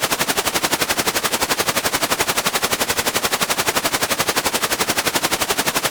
HERICOPTOR-S.WAV